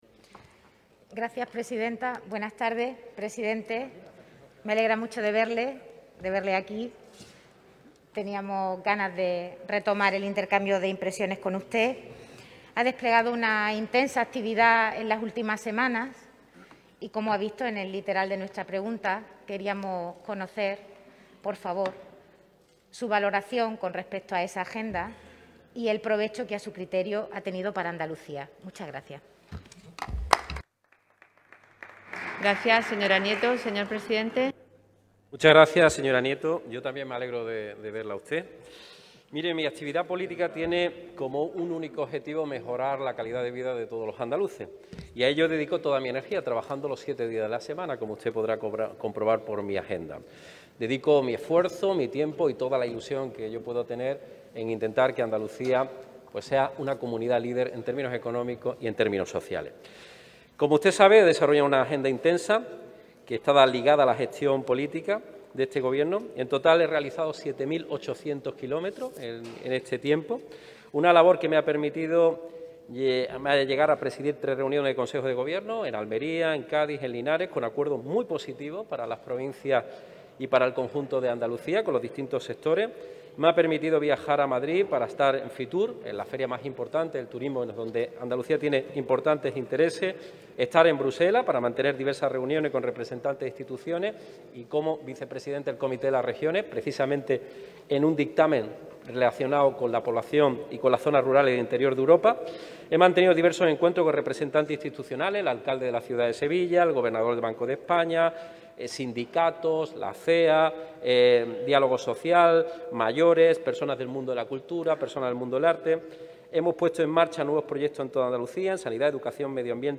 En sesión de control con el presidente de la Junta, Nieto ha señalado que “su imagen de líder y de moderado ya ha sido desmontada por la realidad”.